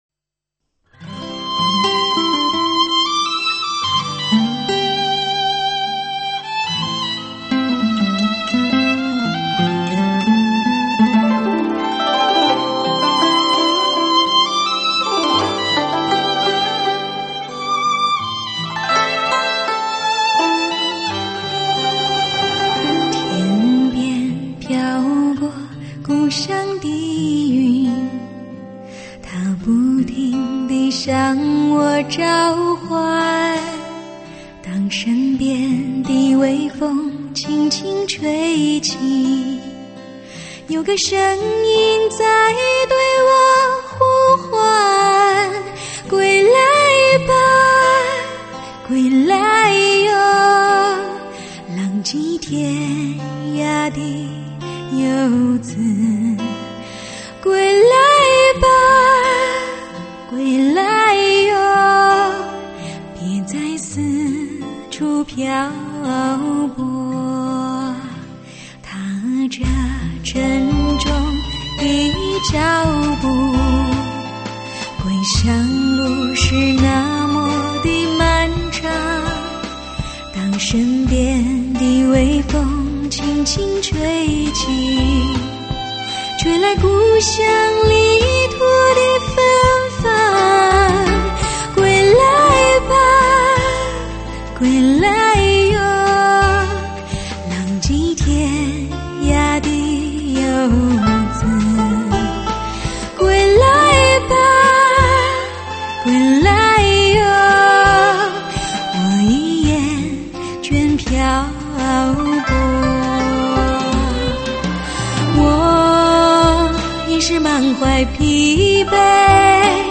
一种舒服得令人想掉泪的感动
绝对经典的音乐情感，绝世稀珍的女声，带您进入美妙的音乐天堂。